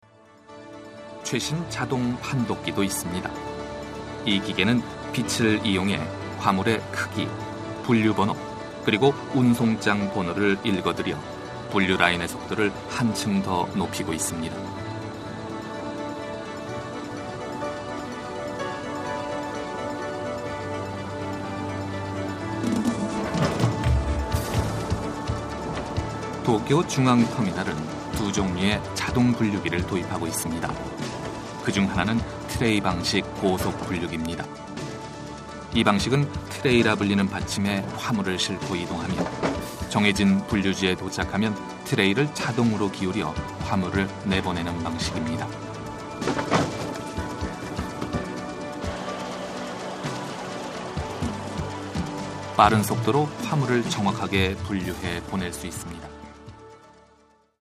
韓国語ナレーター 韓国語ナレーション